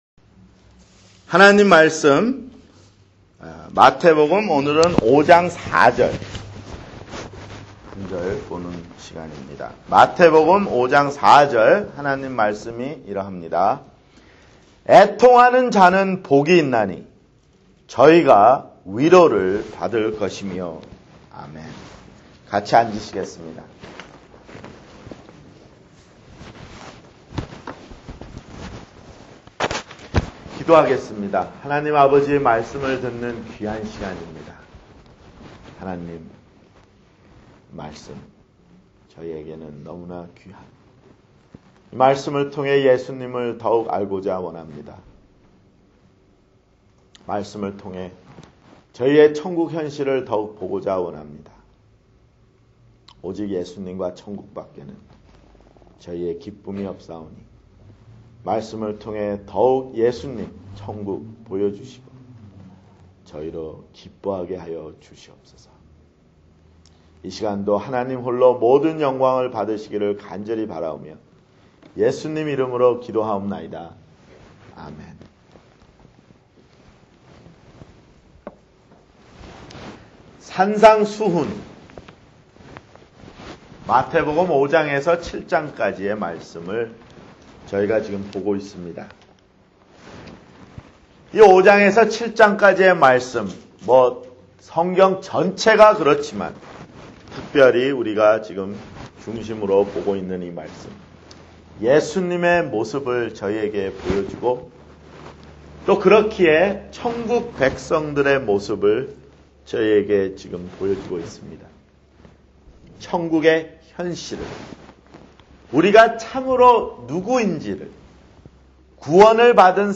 [주일설교] 마태복음 (15)